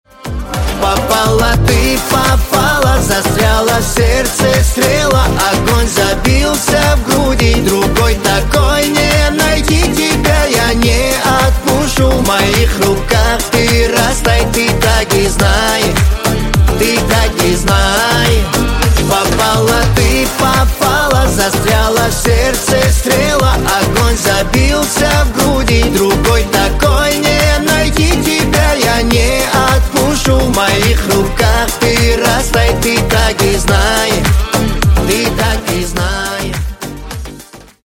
Кавказские Рингтоны
Поп Рингтоны